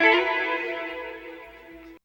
137 GTR 6 -R.wav